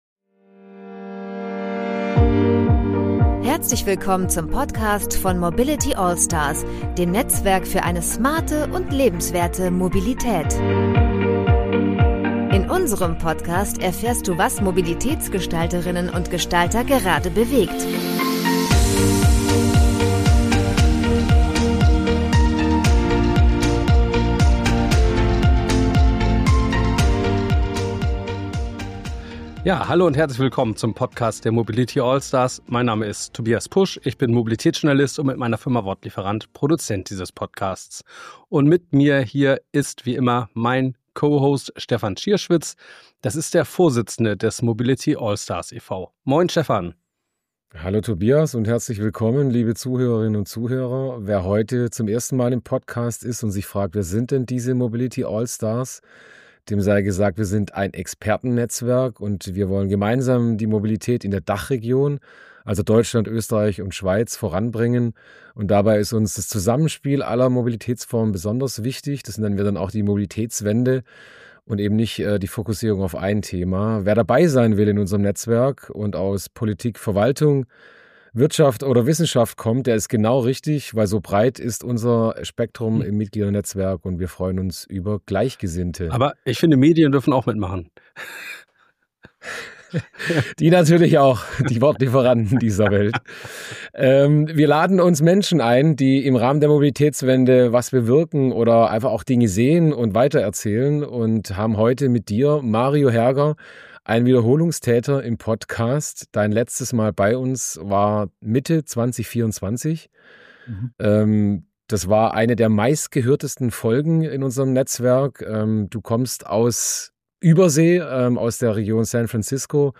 Ein Gespräch über Technologie, Ethik, Wirtschaftlichkeit – und die Frage, ob wir als Gesellschaft bereit sind, schneller zu denken als zu regulieren.